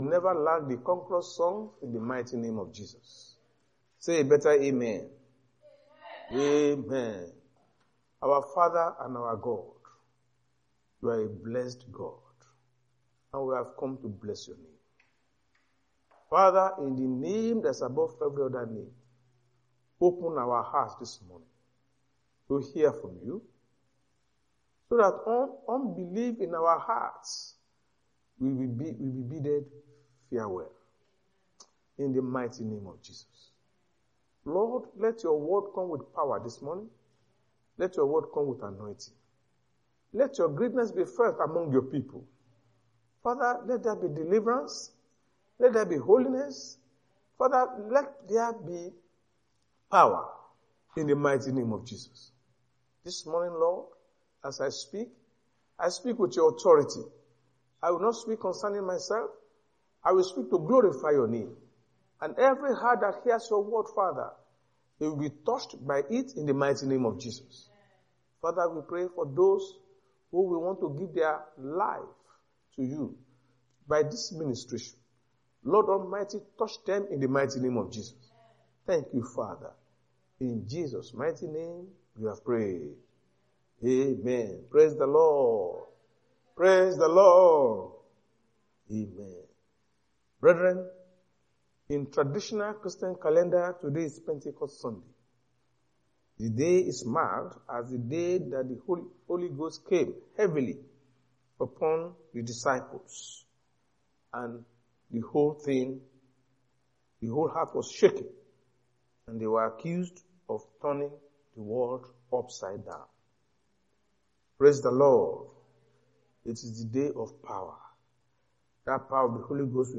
Redeemed Christian Church of God-(House Of Glory) sunday sermons.